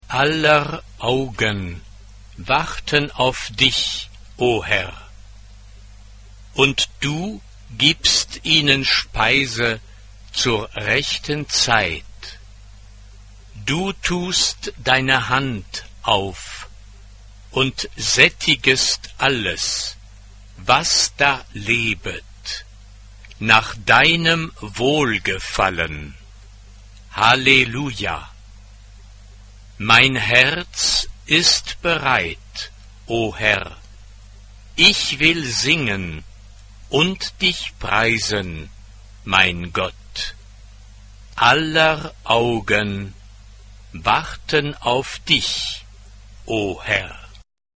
Instrumente: Klavier (1)
Tonart(en): f-moll